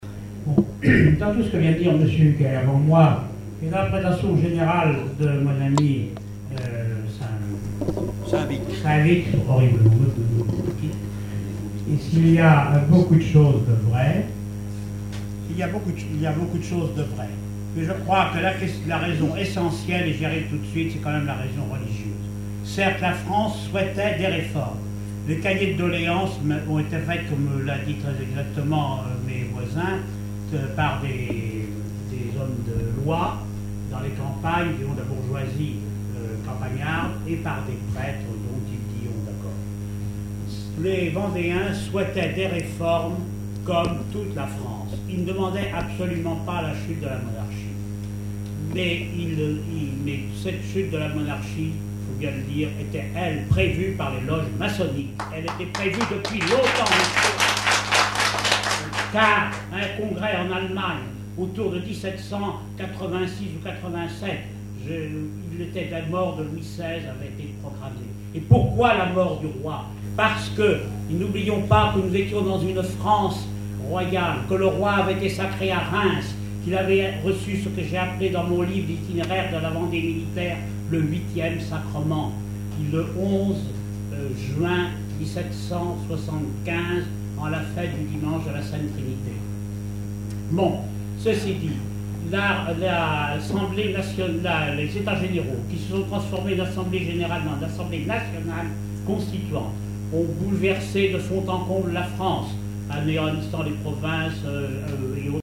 congrès, colloque, séminaire, conférence
Conférence de la Société des écrivains de Vendée
Catégorie Témoignage